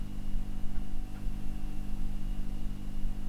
lampbuzz.ogg